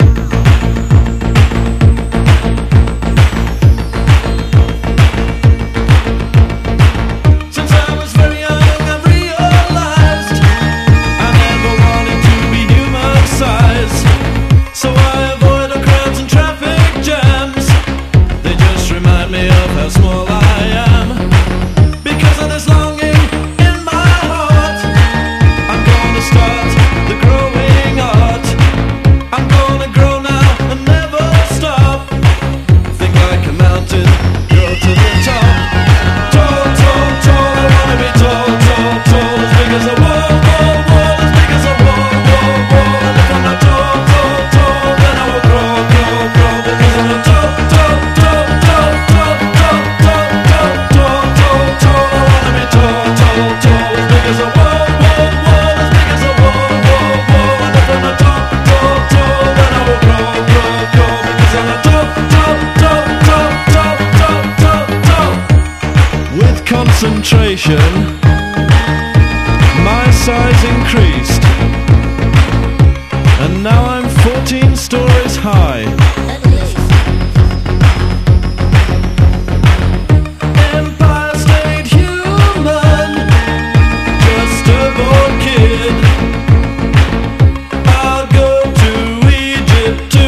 NEO ACOUSTIC
エレピが疾走し爽やかなハーモニーがきらきら輝く